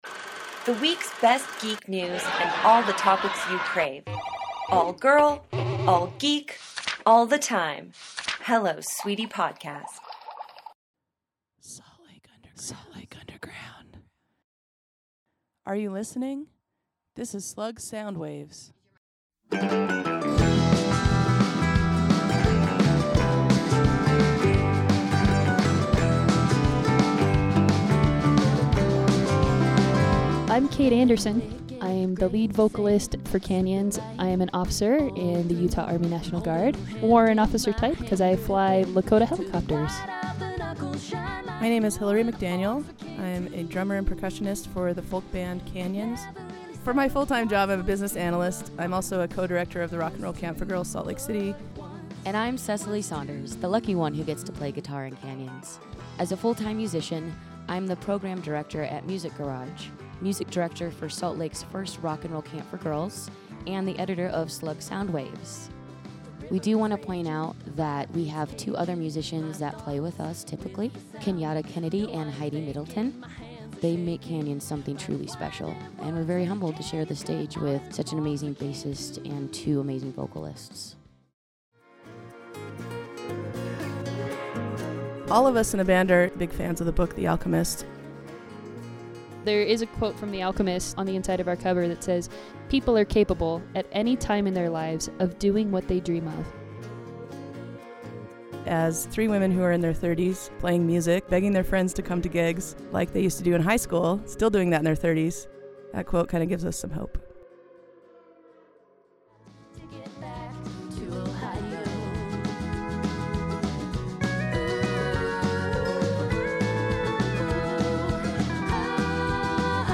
• Background music by Canyons